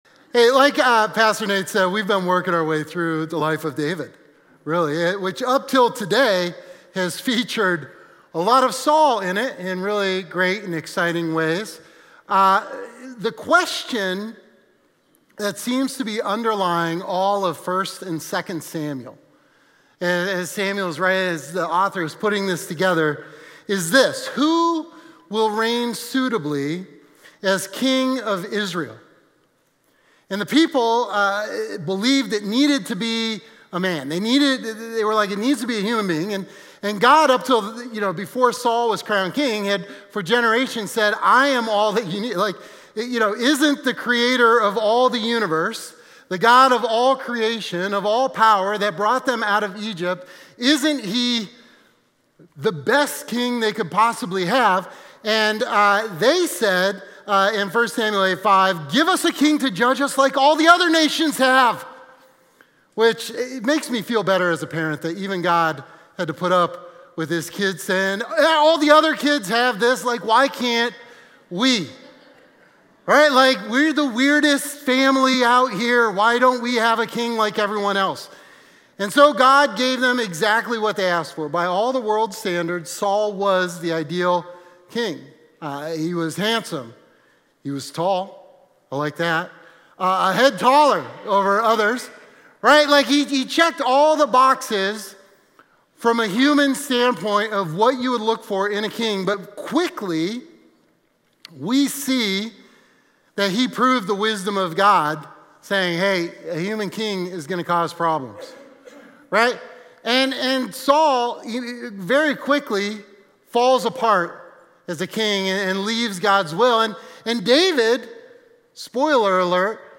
Sermon context is Luke 24:5-6.
Brandywone-Sermon-4.13.mp3